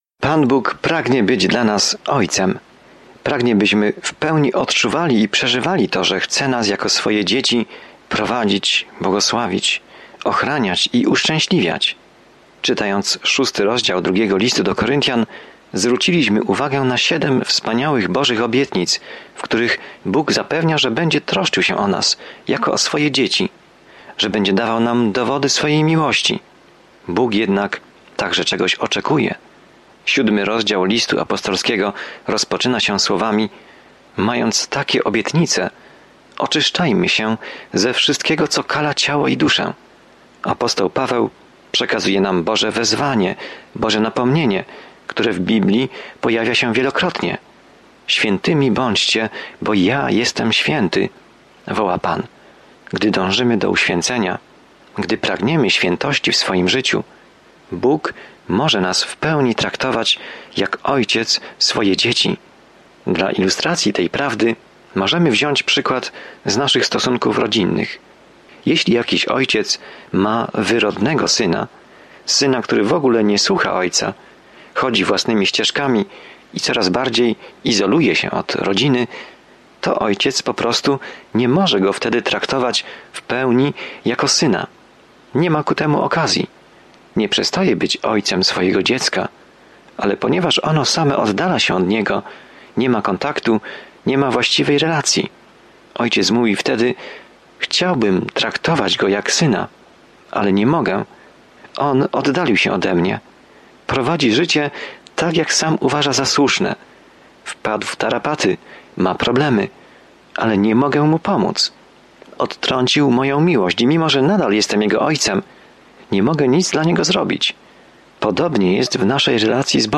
Pismo Święte 2 Koryntian 7:1-16 Dzień 12 Rozpocznij ten plan Dzień 14 O tym planie Radość relacji w Ciele Chrystusa jest podkreślona w drugim Liście do Koryntian, gdy słuchasz studium audio i czytasz wybrane wersety słowa Bożego. Codziennie podróżuj przez 2 List do Koryntian, słuchając studium audio i czytając wybrane wersety ze słowa Bożego.